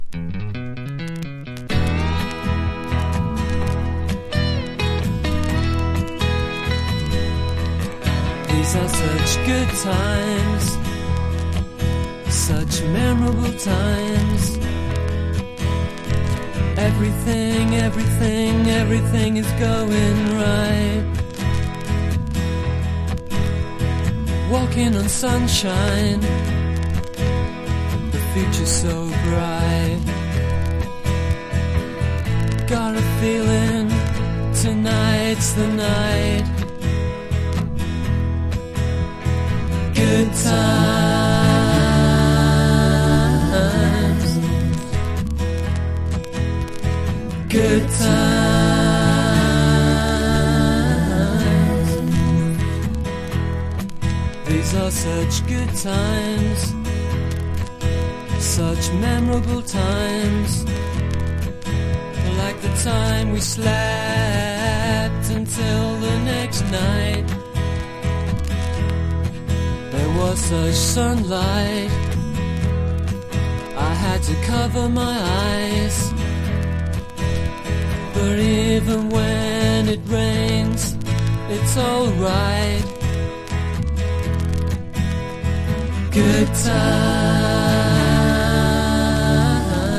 NEO ACOUSTIC / GUITAR POP# ALTERNATIVE / GRUNGE# 90’s ROCK